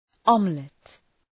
Προφορά
{‘ɒmlət, ‘ɒmlıt}